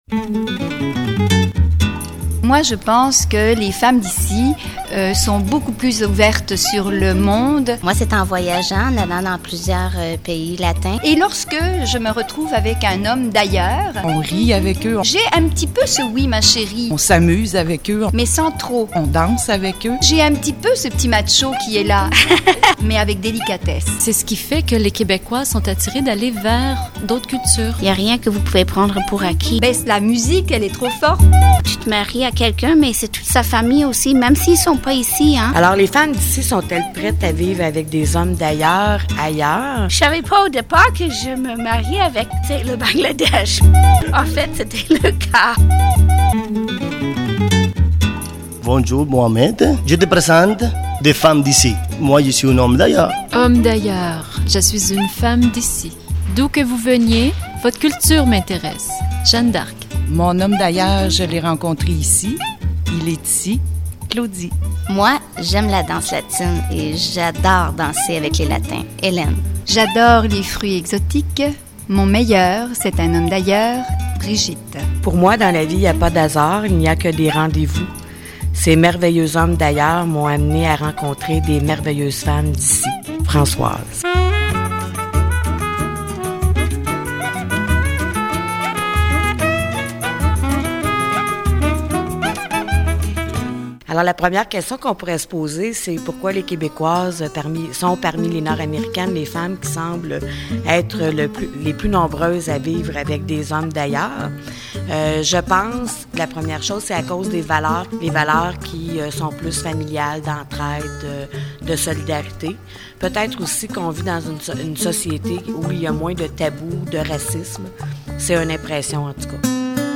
Reportage
J'ai rencontré les 5 fondatrices de l'association.